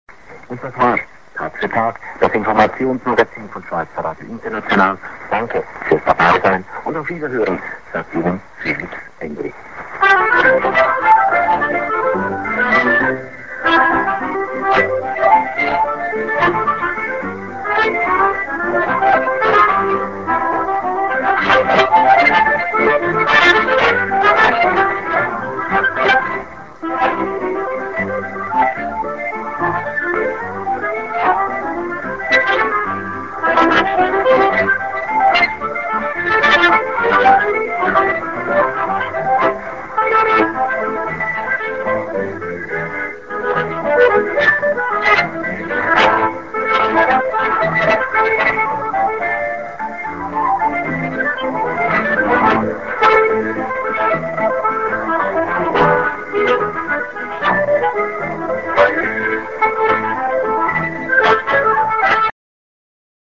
end ANN(man)+10":ヨーデルで終わる